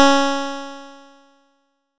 nes_harp_Cs4.wav